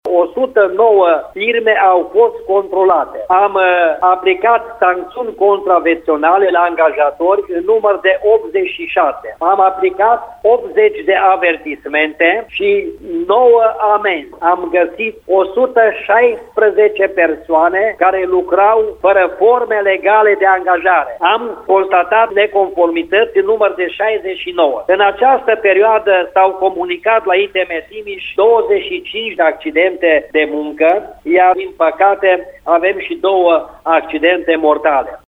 Peste 100 de persoane au fost identificate fără forme legale de muncă, spune inspectorul-șef al ITM Timiș, Pavel Kasay.